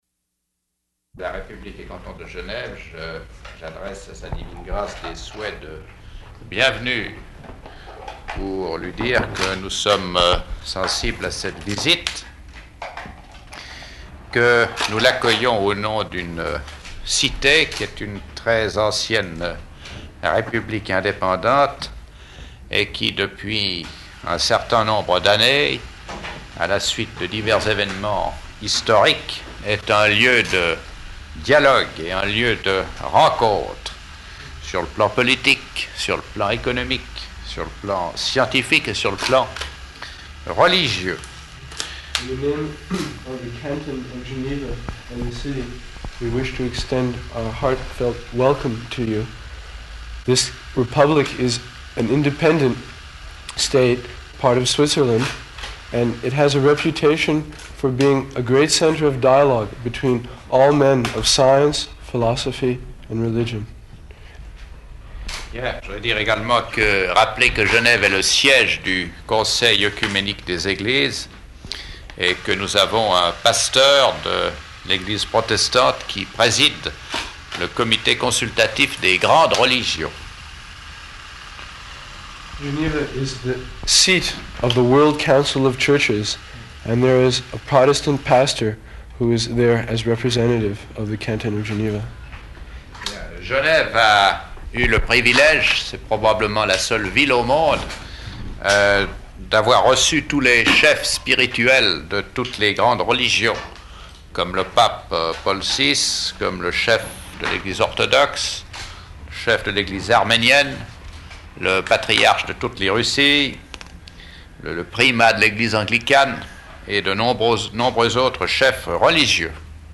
Śrīla Prabhupāda Welcomed by Governor at Hotel de Ville --:-- --:-- Type: Lectures and Addresses
Location: Geneva